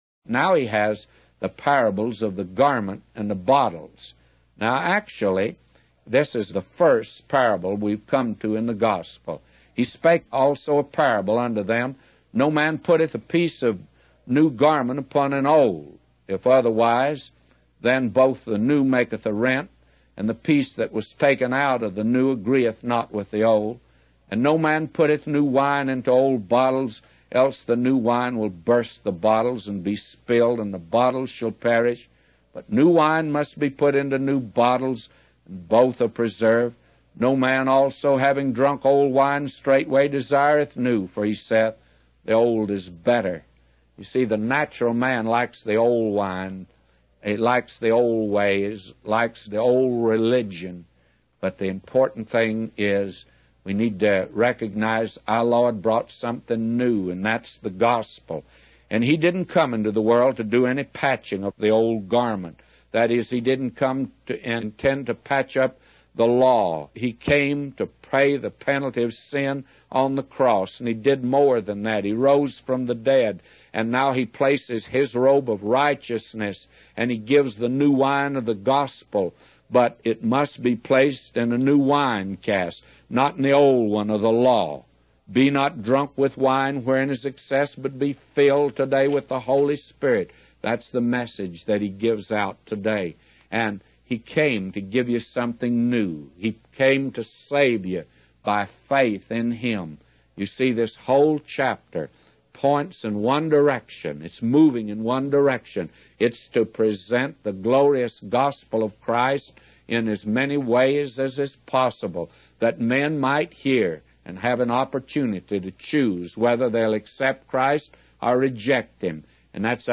Daily Bible Reading
Evening Bible Reading - Luke 5